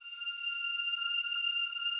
Added more instrument wavs
strings_077.wav